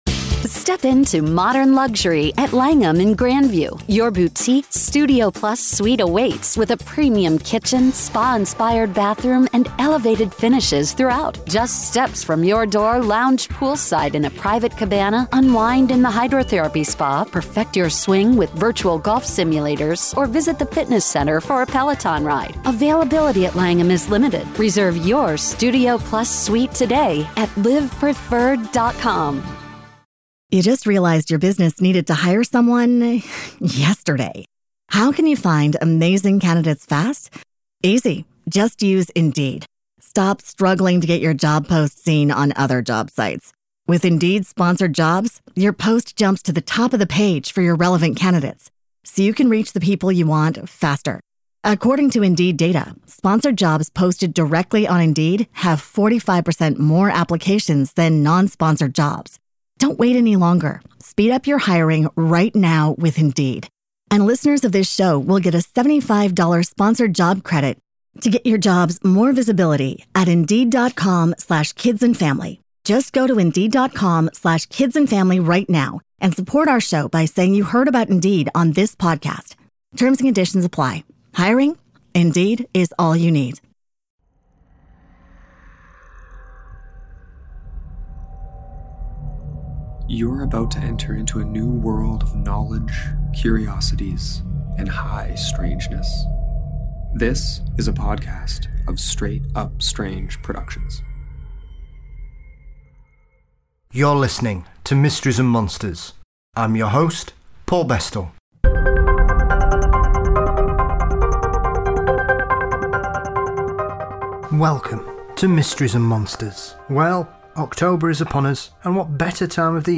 We discuss the origin of the vampires, the contrasts in folklore from around the world, what actually counts as a vampire and the variety of ways to deal with the undead, once they return from the grave.